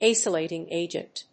acylating+agent.mp3